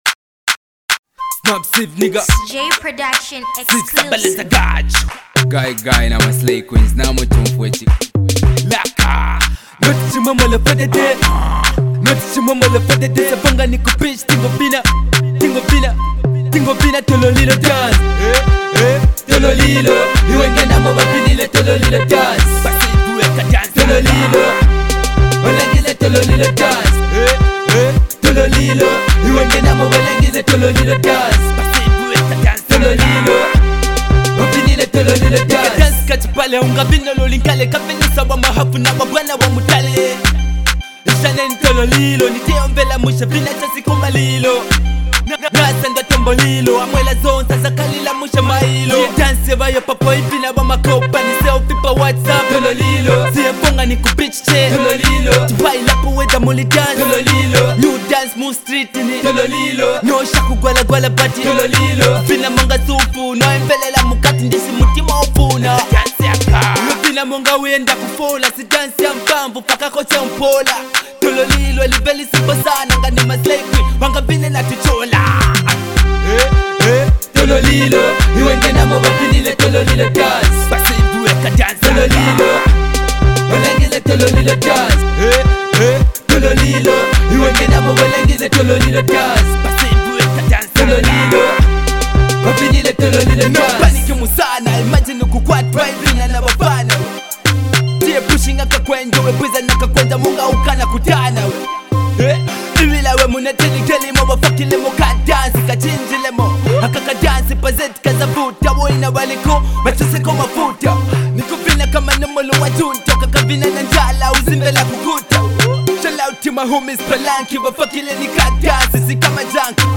Here is a dope dancehall tune